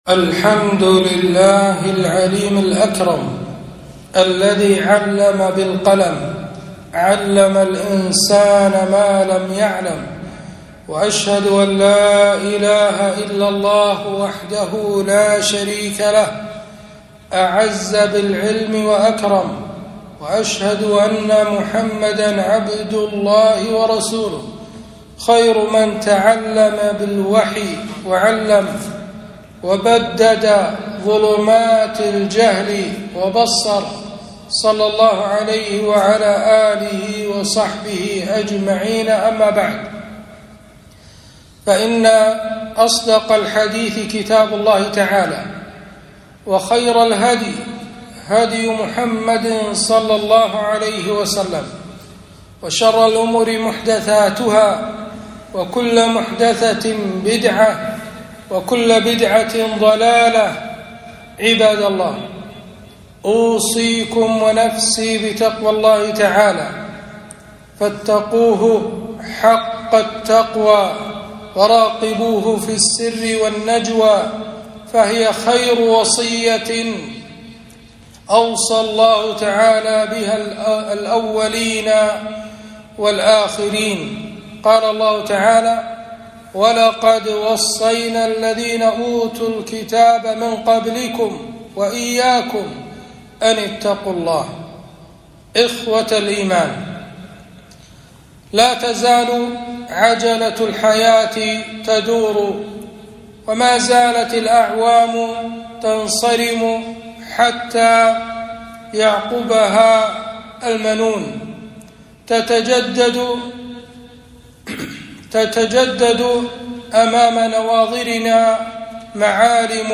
خطبة - وقفات مع بداية العام الدراسي الجديد